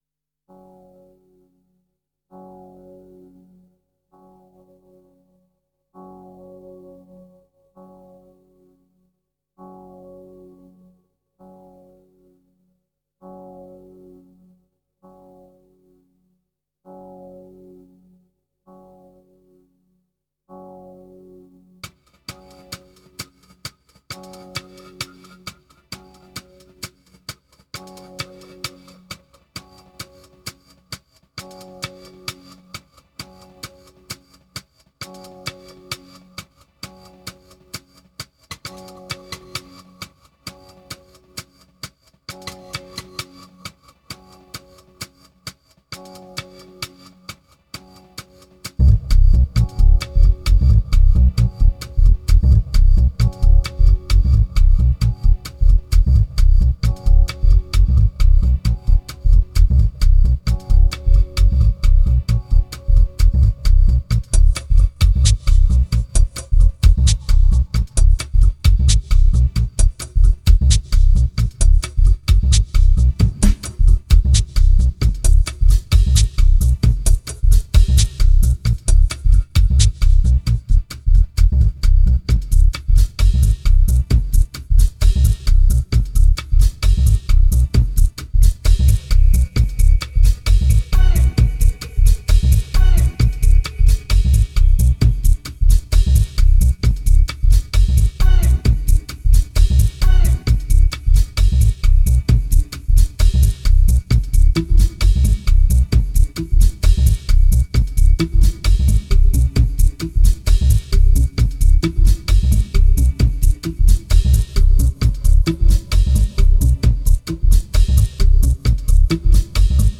2324📈 - 19%🤔 - 132BPM🔊 - 2010-07-04📅 - -91🌟